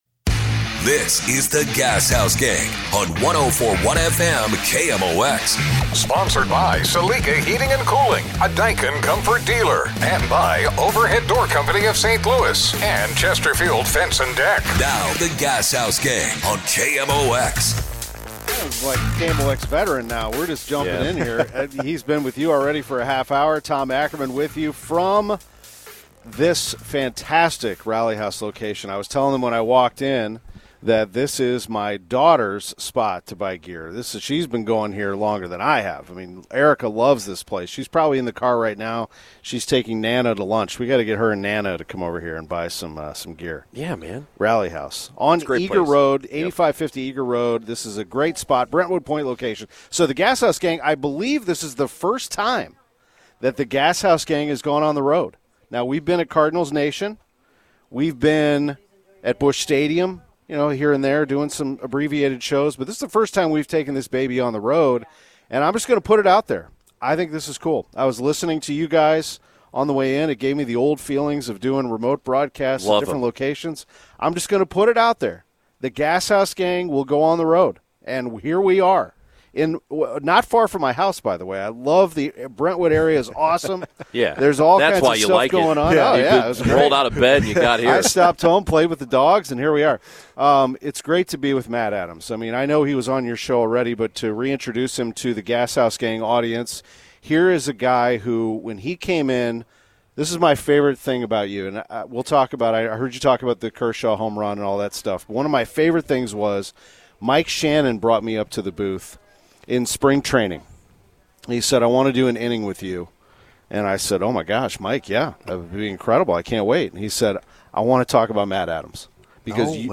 The Gashouse Gang - Live with Matt Adams from Rally House in Brentwood